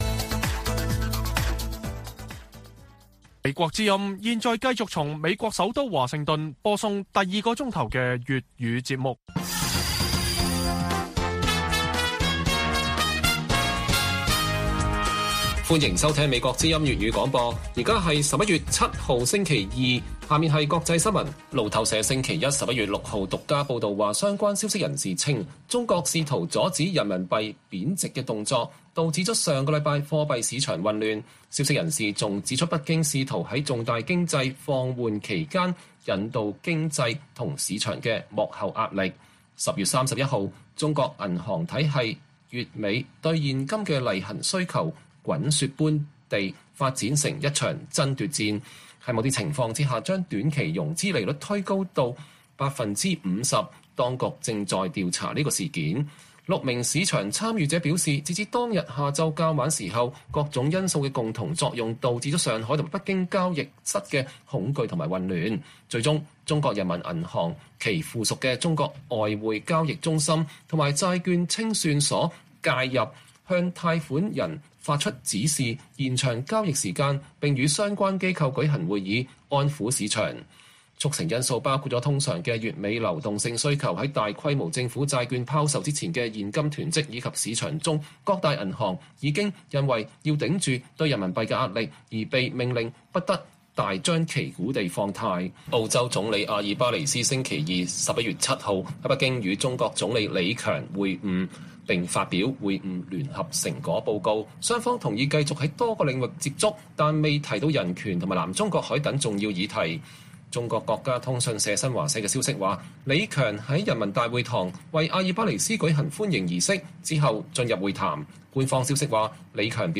粵語新聞 晚上10-11點: 路透社獨家報導說罕見貨幣市場困境背後顯示出中國糾結的優先事項